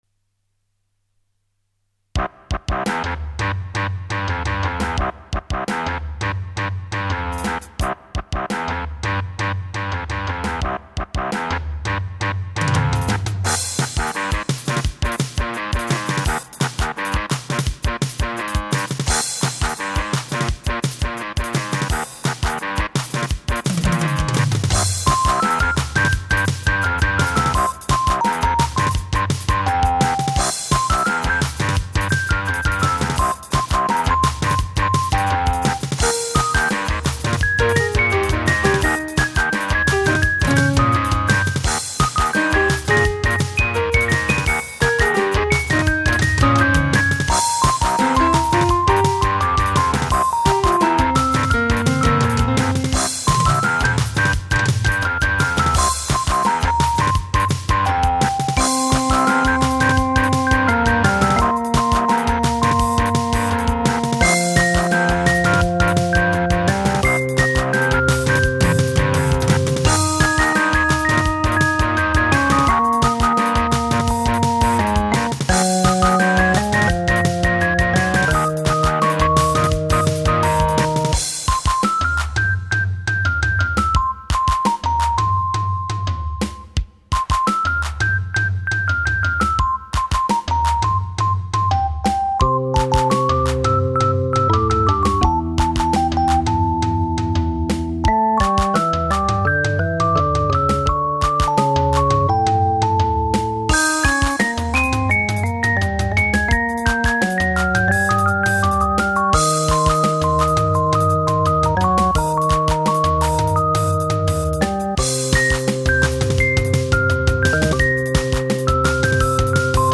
Musique de film de gangters ou générique de talk-show d'après-midi, c'est vous qui voyez.